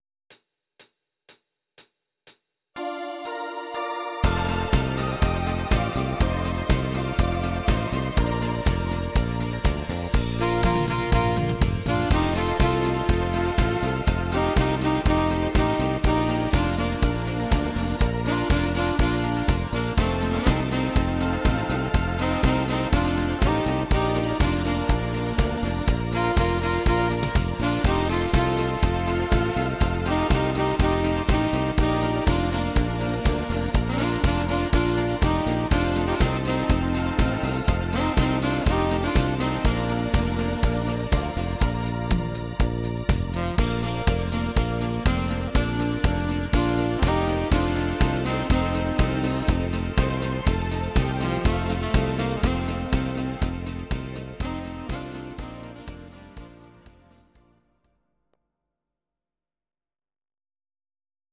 Discofox on Sax